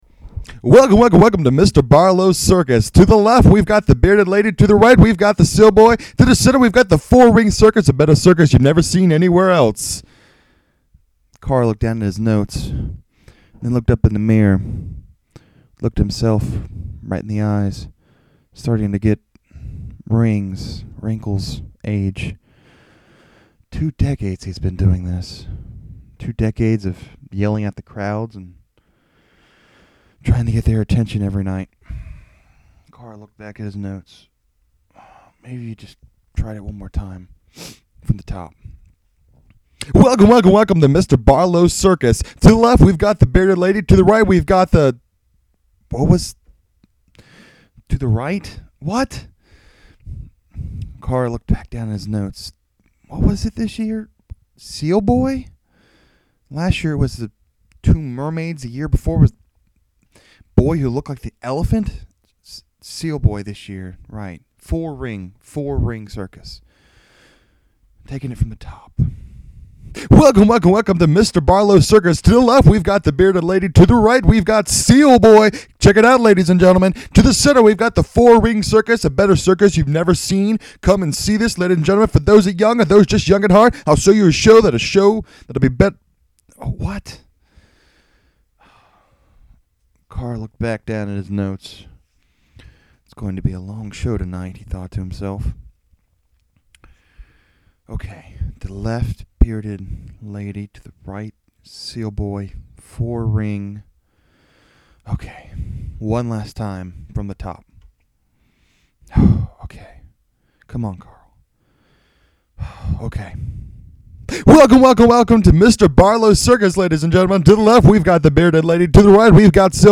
I’ve been trying to hold a microphone and talk.
The sound levels vary widely. I get really quiet in sections and then really loud.